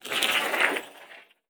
Ice Skate A.wav